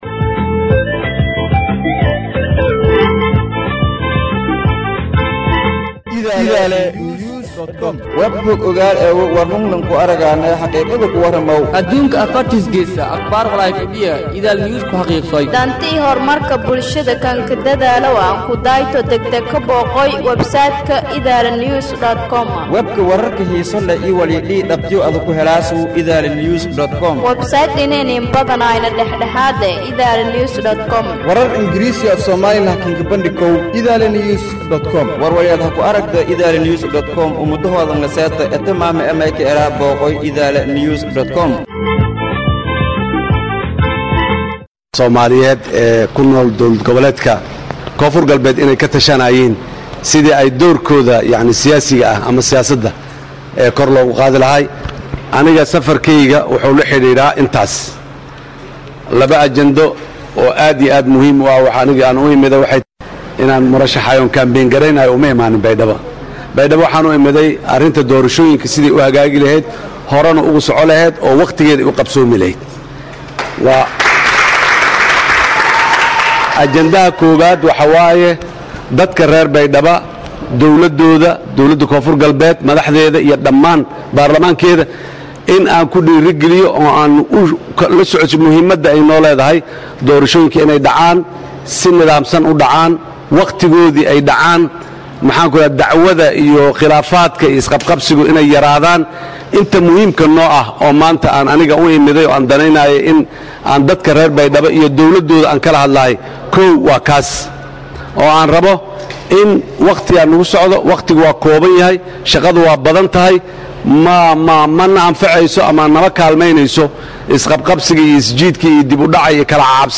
Dhageyso: Nuxurka Khudbada Ujeeda Safarka Xasan Sheekh Ee Baydhabo